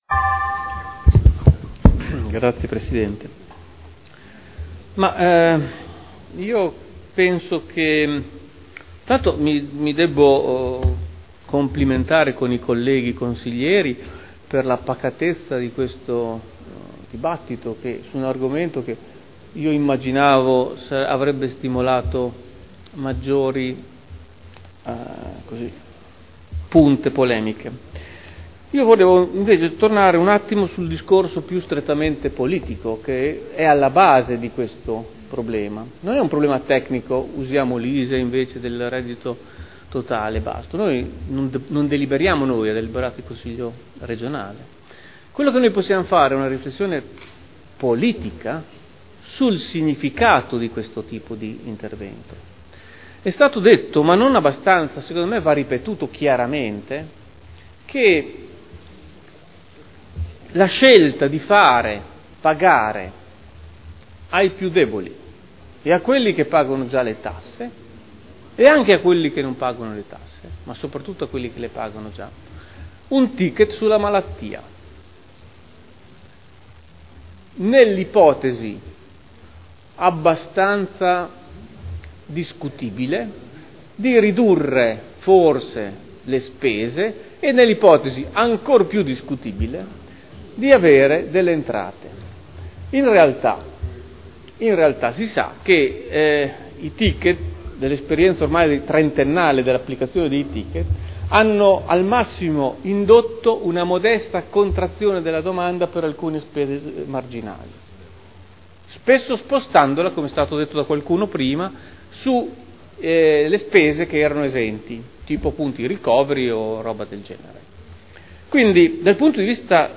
Luigi Alberto Pini — Sito Audio Consiglio Comunale
Seduta del 12/09/2011. Dibattito su Ordini del Giorno relativi all'introduzione del ticket sanitario.